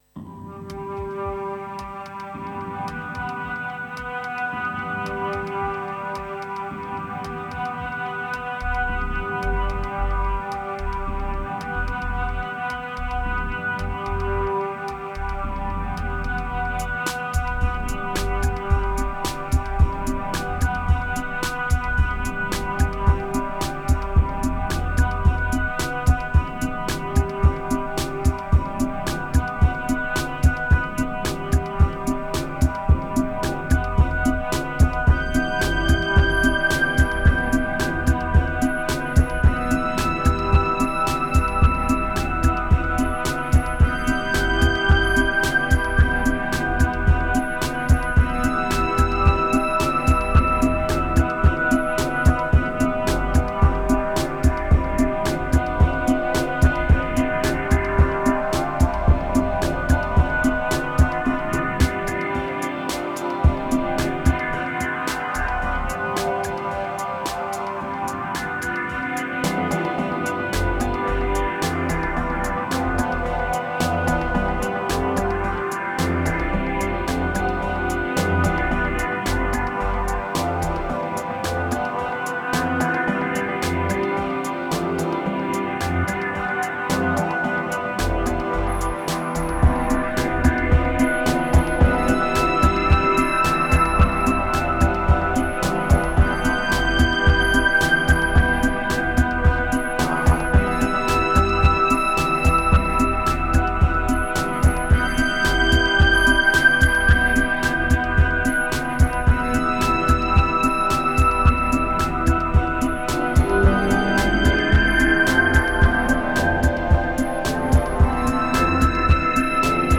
504📈 - 87%🤔 - 55BPM🔊 - 2021-10-16📅 - 258🌟